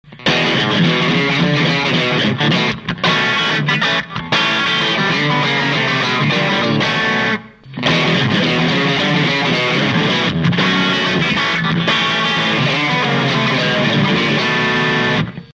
0.082はファット過ぎますし0.056だと変化が地味すぎるので0.068μＦに、とりあえずは